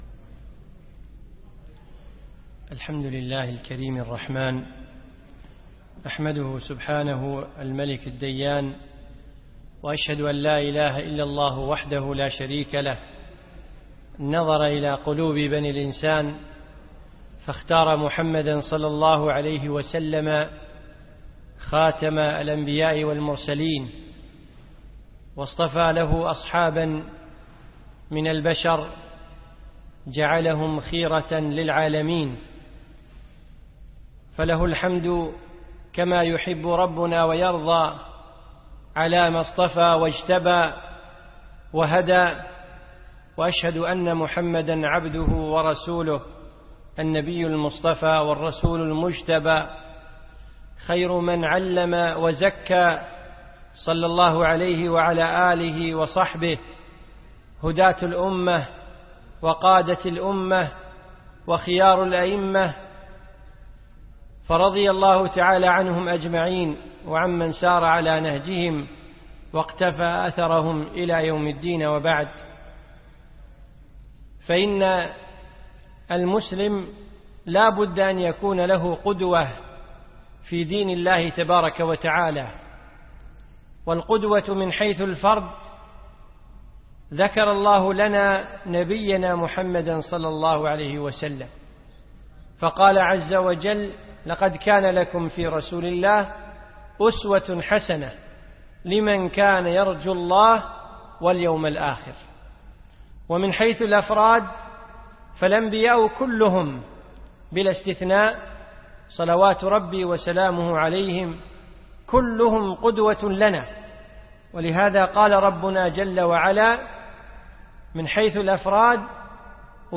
من دروس الشيخ في دولة الإمارات في رمضان 1436